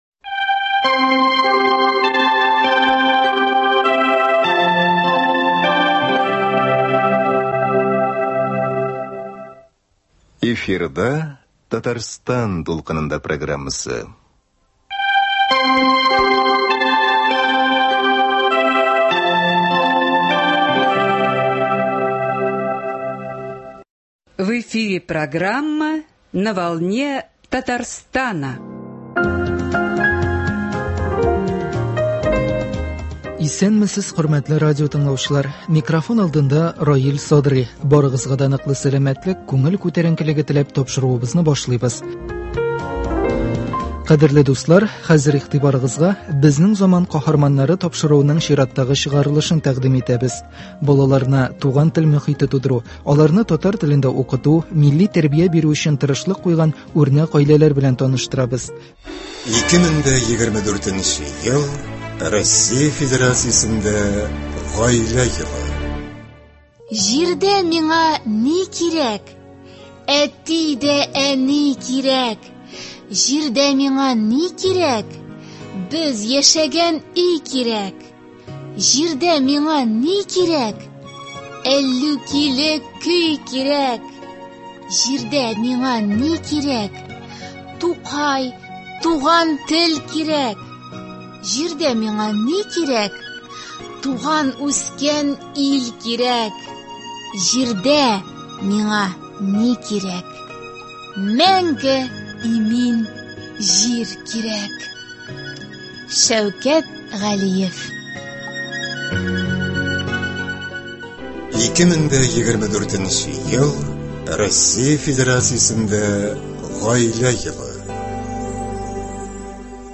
2024 ел — Россиядә Гаилә елы. Тапшыруыбызның бүгенге каһарманнарын да без үзебезгә, Татарстан радиосы студиясенә кунакка дәштек.